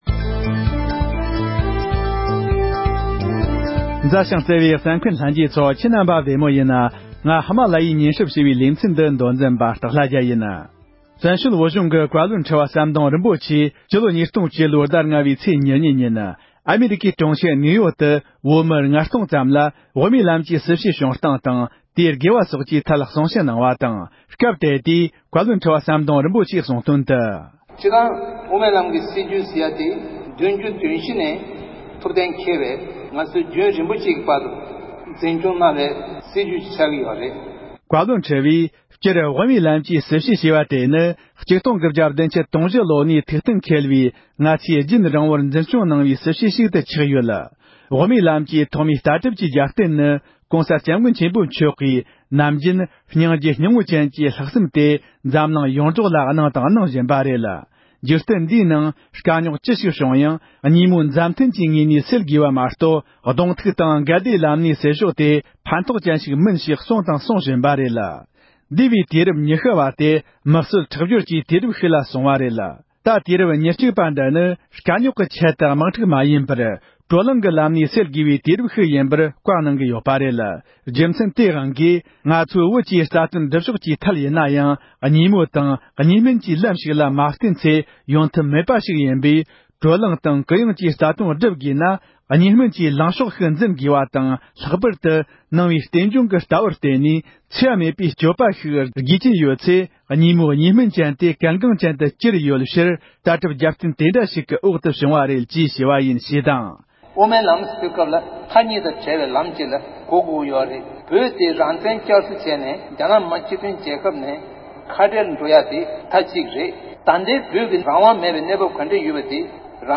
བཀའ་བློན་ཁྲི་པ་མཆོག་ནས་དབུ་མའི་ལམ་གྱི་སྐོར་གསུང་བཤད།
བཀའ་བློན་ཁྲི་པ་མཆོག་གིས་ཨ་རིའི་གྲོང་ཁྱེར་ནིའུ་ཡོརྐ་ཏུ་བོད་མི་ལྔ་སྟོང་ལྷག་ལ་དབུ་མའི་ལམ་གྱི་སྲིད་བྱུས་ཀྱི་ངོ་བོ་དང་རྒྱབ་རྟེན་ཐད་གསུང་བཤད།